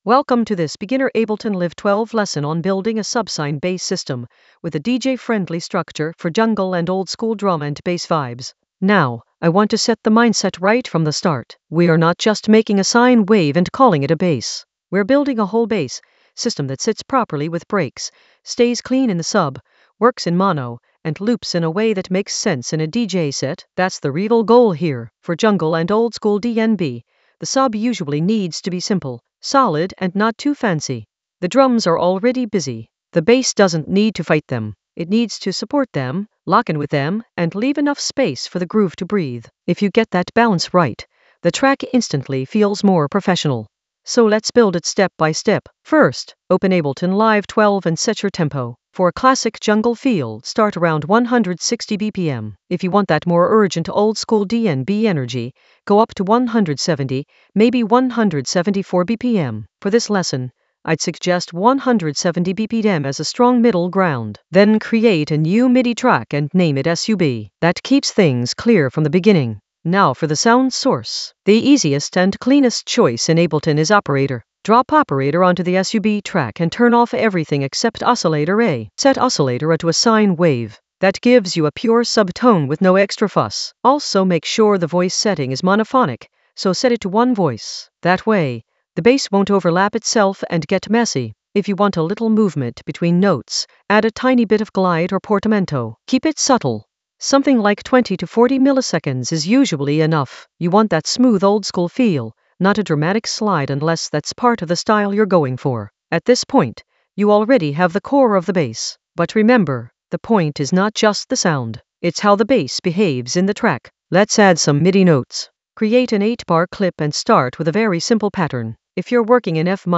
An AI-generated beginner Ableton lesson focused on System for subsine with DJ-friendly structure in Ableton Live 12 for jungle oldskool DnB vibes in the Sound Design area of drum and bass production.
Narrated lesson audio
The voice track includes the tutorial plus extra teacher commentary.